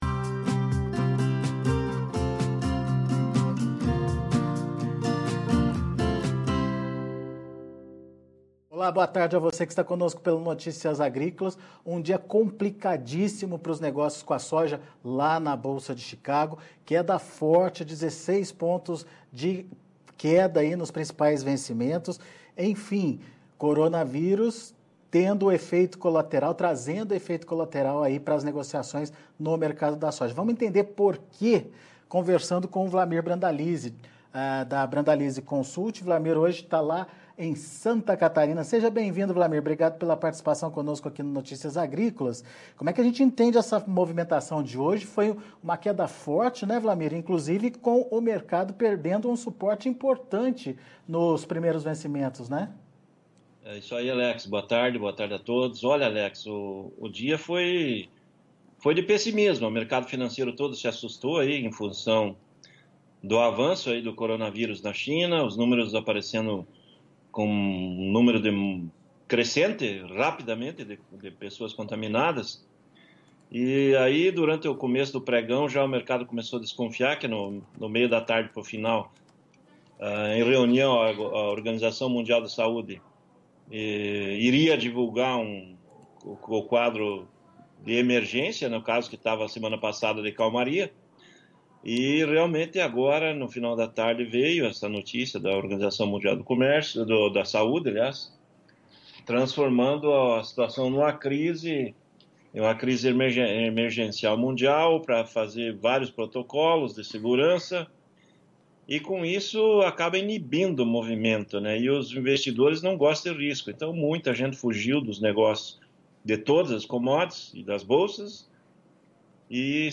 Fechamento do Mercado da Soja - Entrevista